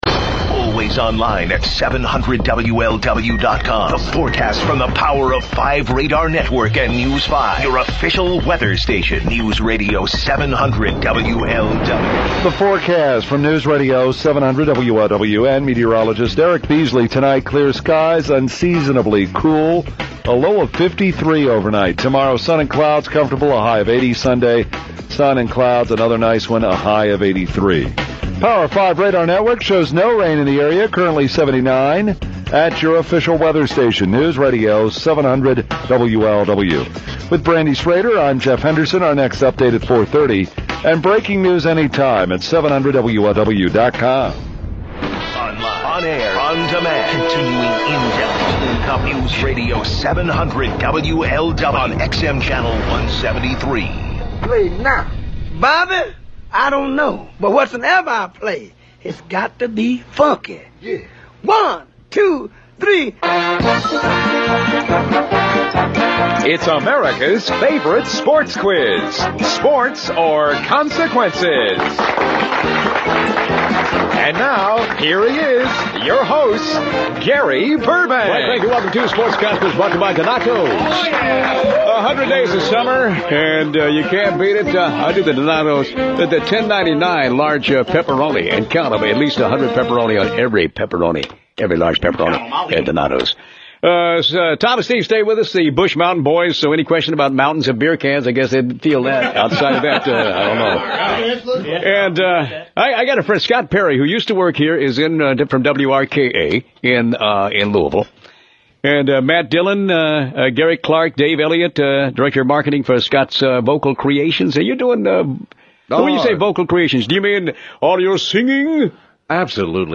WLW Proclamation Aircheck